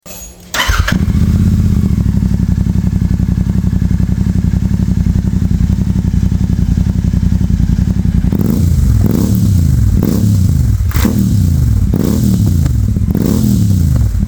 EXPERIENCE THE ROAR! CLICK BELOW TO LISTEN TO OUR EXHAUST SOUND.